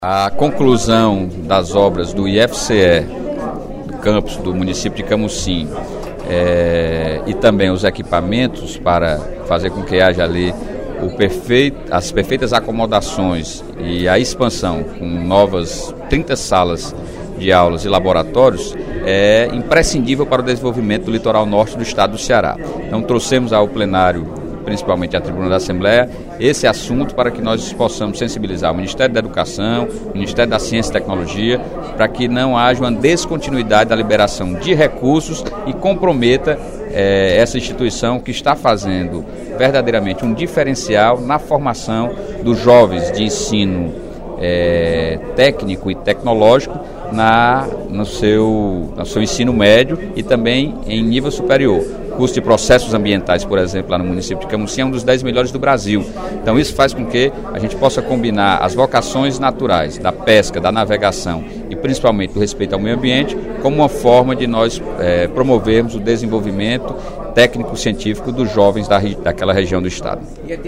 Em pronunciamento no primeiro expediente da sessão plenária desta terça-feira (01/03), o parlamentar disse que a solicitação para que os recursos destinados à construção do prédio continuem a chegar foi feita pelos professores do Campus.